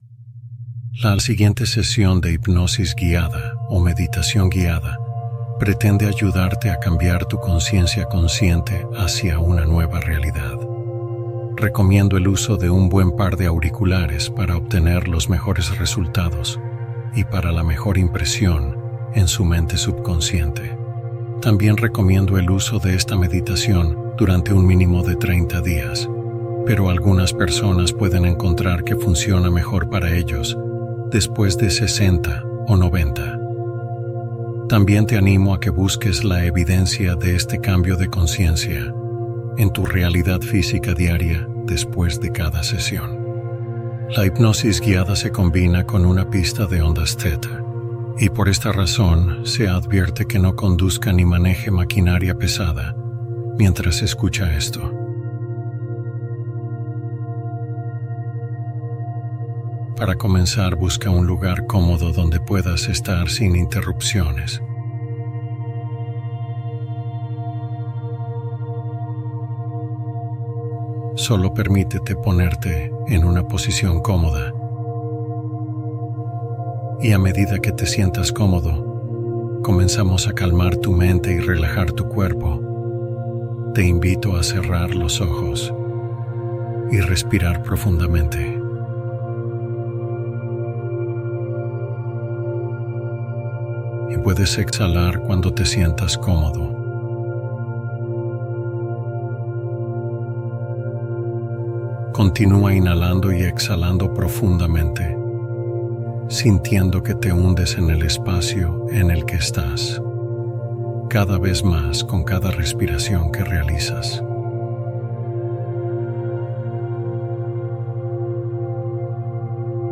Crea Una Nueva Vida en Año Nuevo | Meditación de Renovación